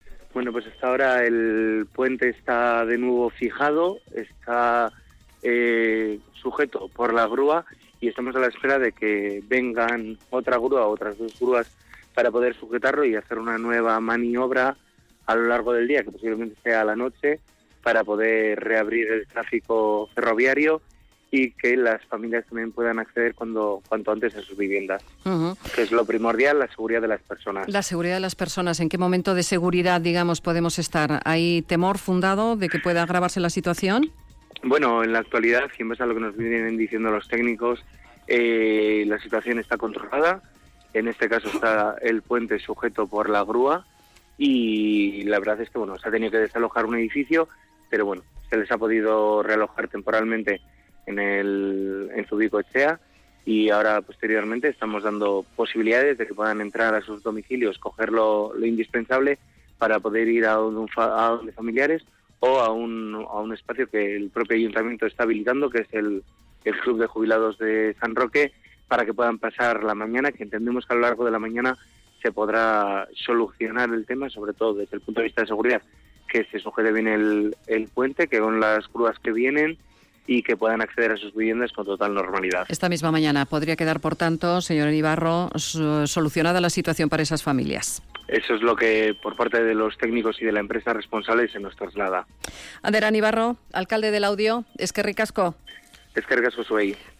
Sobre ello hemos hablado con el alcalde de Laudio.